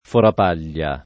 vai all'elenco alfabetico delle voci ingrandisci il carattere 100% rimpicciolisci il carattere stampa invia tramite posta elettronica codividi su Facebook forapaglie [ forap # l’l’e ] o forapaglia [ forap # l’l’a ] s. m. (zool.); inv.